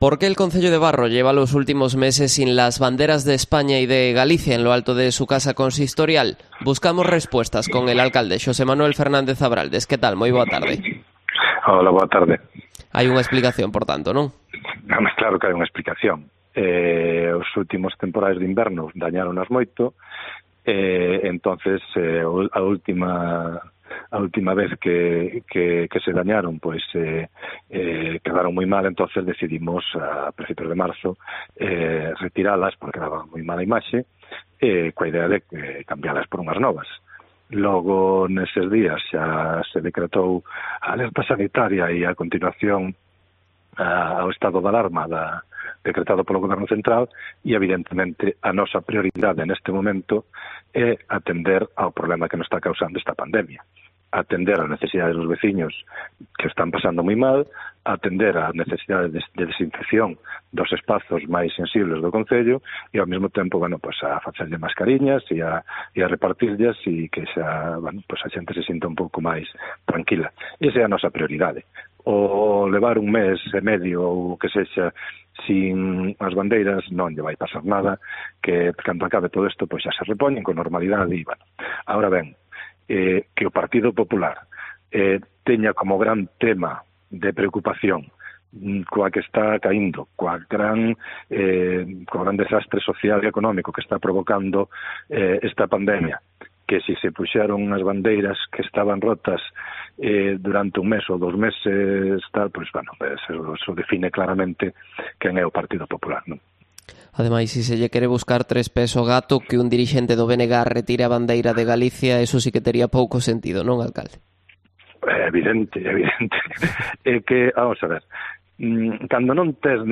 Entrevista a Xosé Manuel Fernández Abraldes, alcalde de Barro